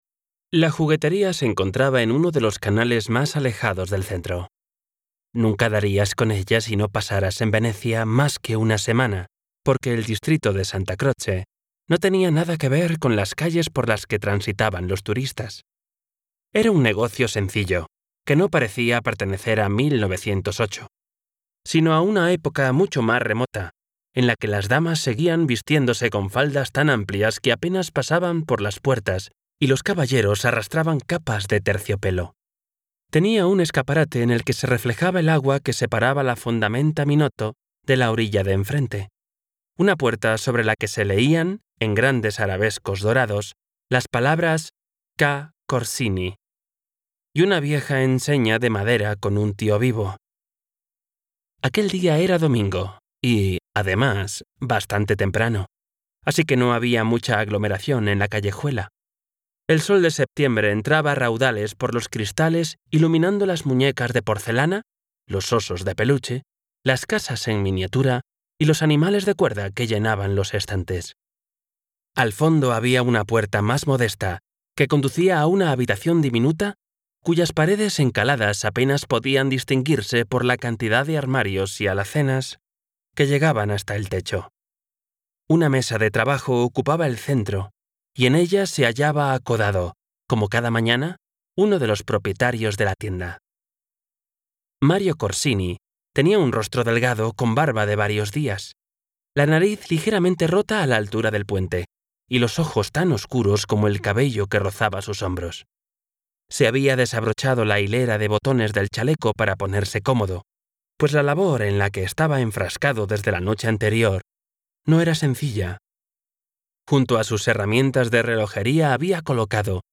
Audiolibro Las eternas (The Eternal Ones)